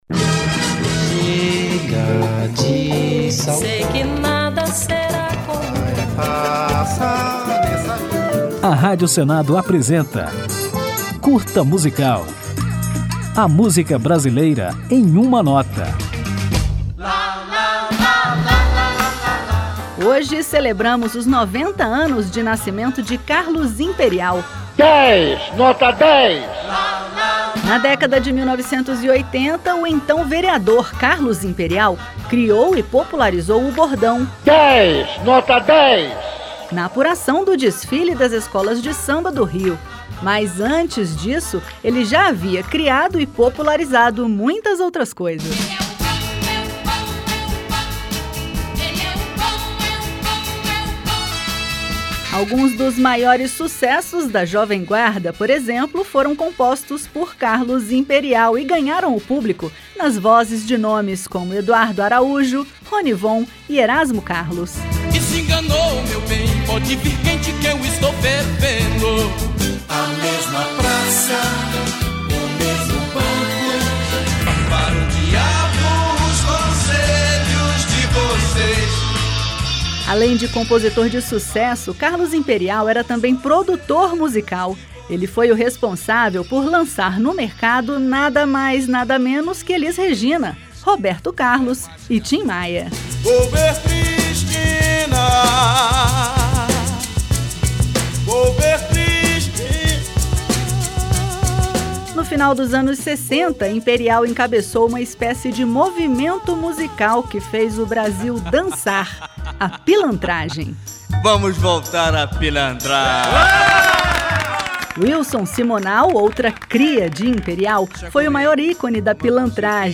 Ao final desta homenagem, que vai apresentar a história de Carlos Imperial, vamos ouvir um de seus grandes sucessos, a música Nem Vem Que Não Tem, eternizada por Wilson Simonal.